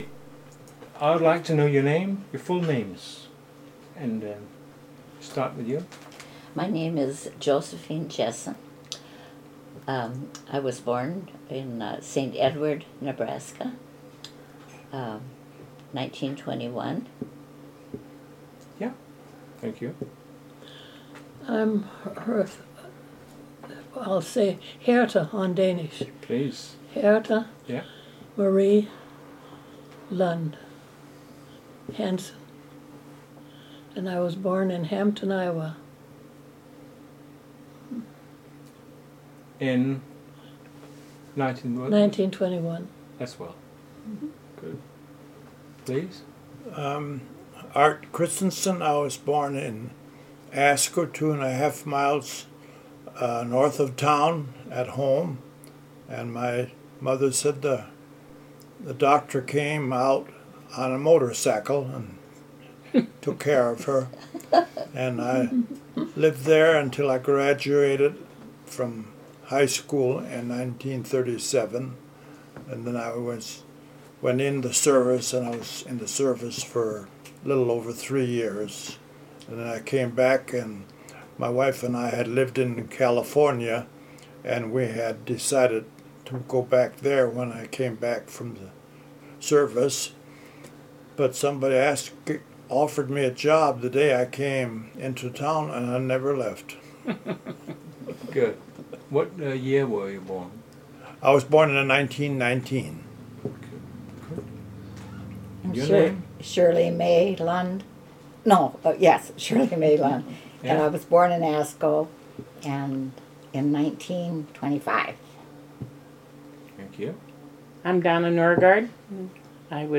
Interviewd